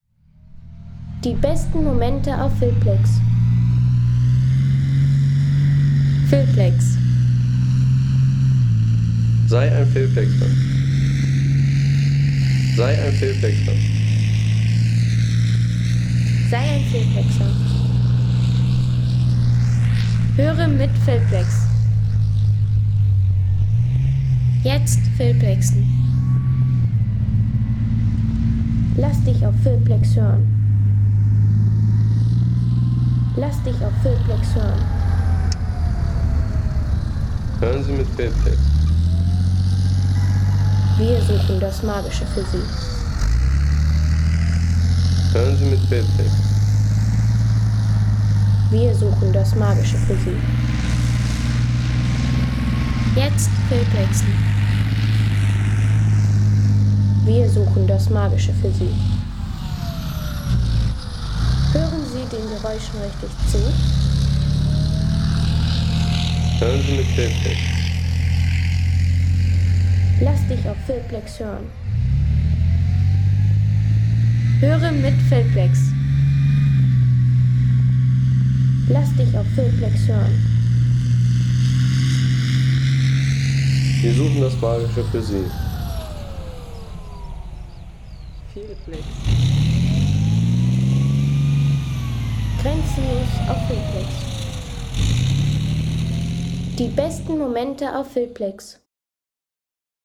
Kampfpanzer - Leopard 2A7
Leopard 2A7: Kraftpaket auf dem Ackerfeld.